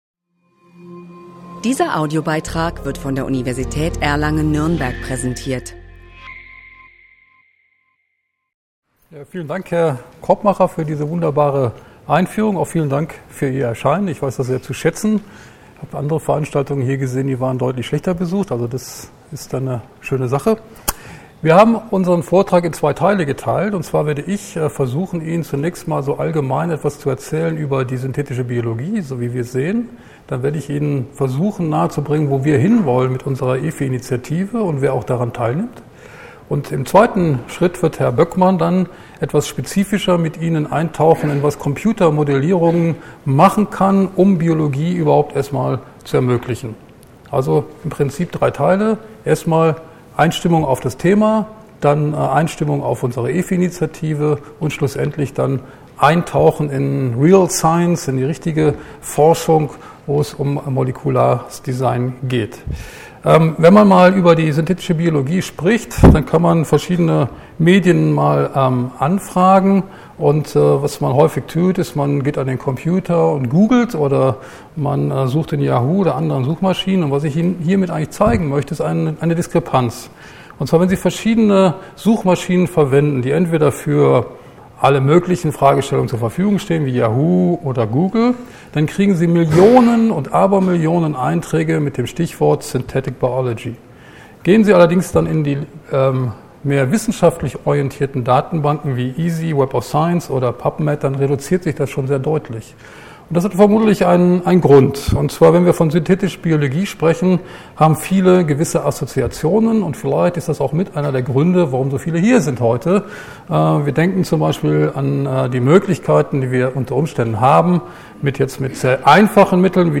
Romantik als Gegenströmung zur Digitalisierung? – Podiumsdiskussion